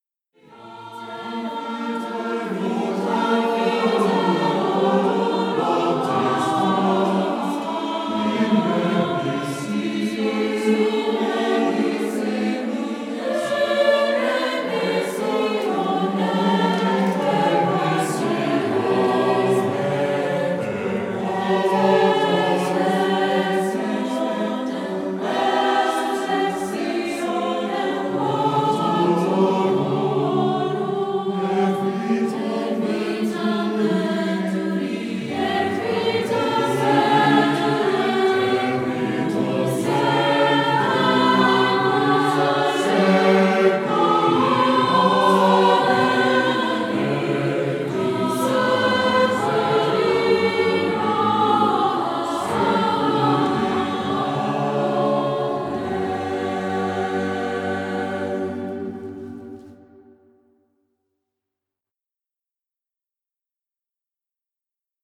Ripped From A Live-Feed • I was able to ‘rip’ a few excerpts from our live-feed, which I thought our readers might enjoy hearing. These come the first half of our Midnight Mass (24 December 2022).